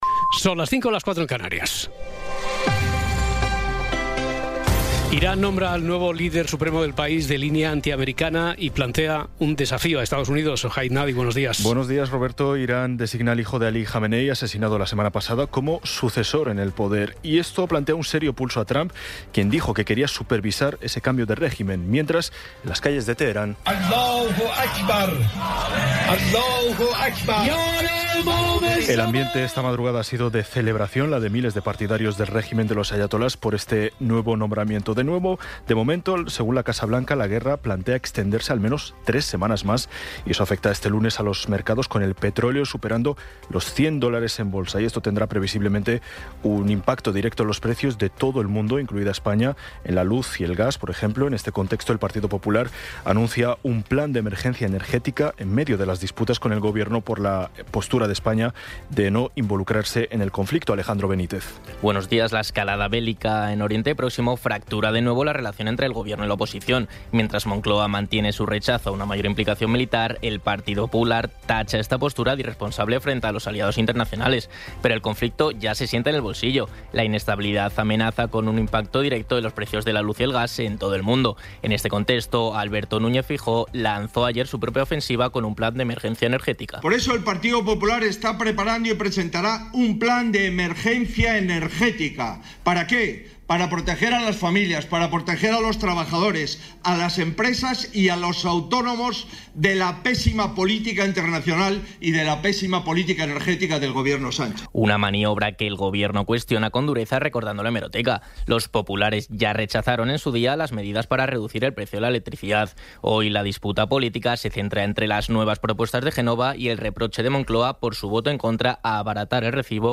Resumen informativo con las noticias más destacadas del 09 de marzo de 2026 a las cinco de la mañana.